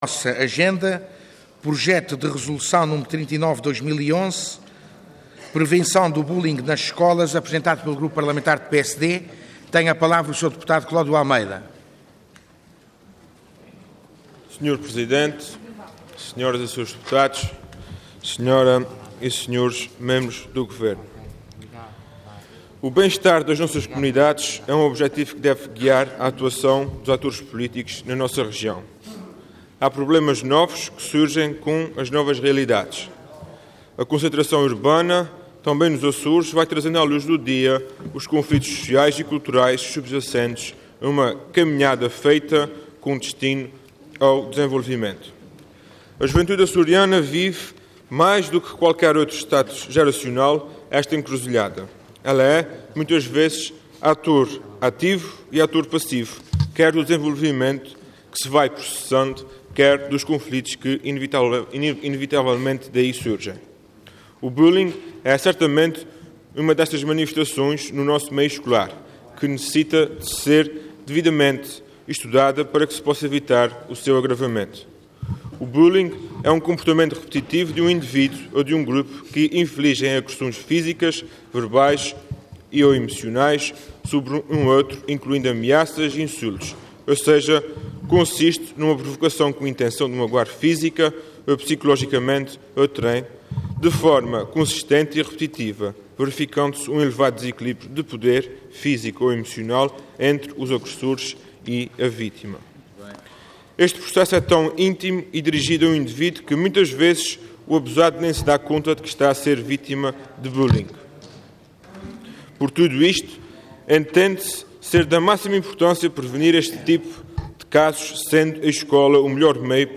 Intervenção Projeto de Resolução Orador Cláudio Almeida Cargo Deputado Entidade PSD